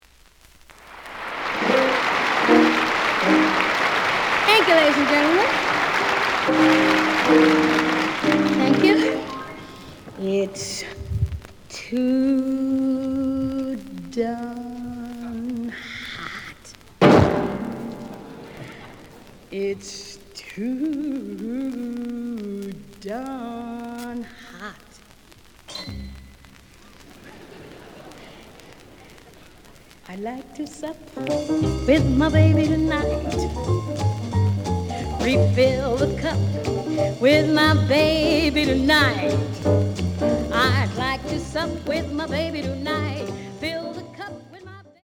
The audio sample is recorded from the actual item.
●Genre: Vocal Jazz
Looks good, but slight noise on both sides.